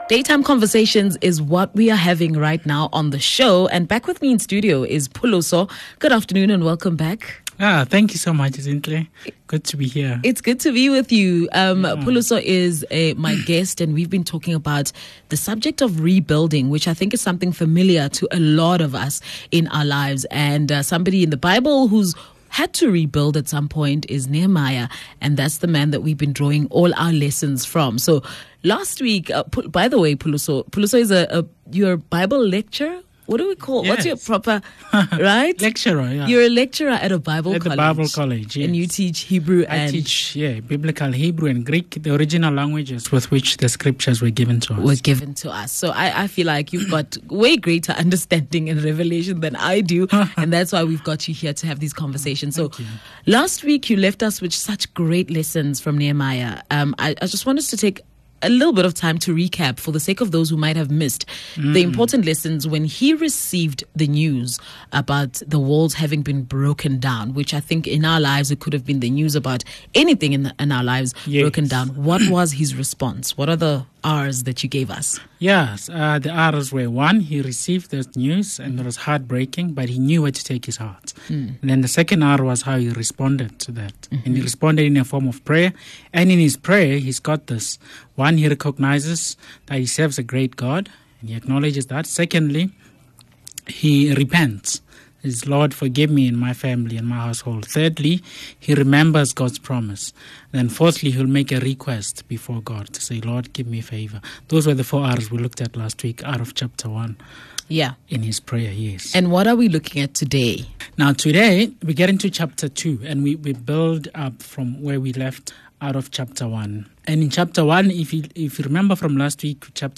On this channel, we share some of our presenters most valuable and encouraging links from their shows, interviews with guests and other other valuable content.